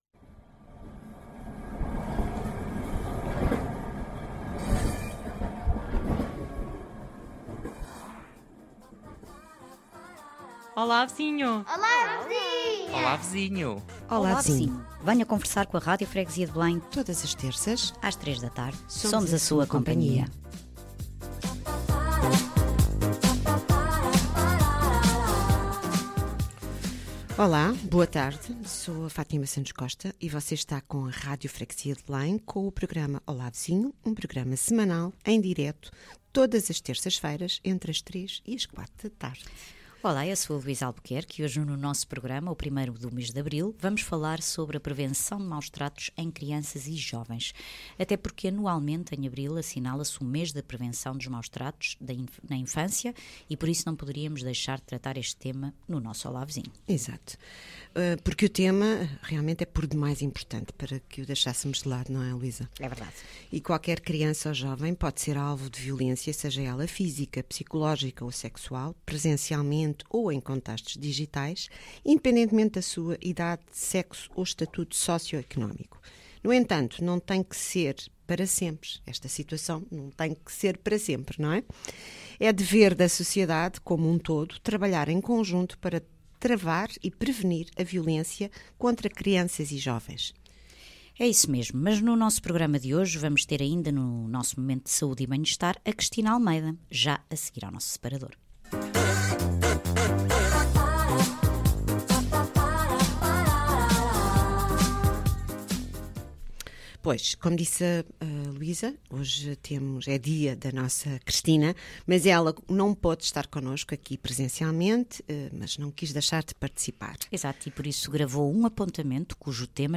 O Seu programa da tarde, na Rádio Freguesia de Belém.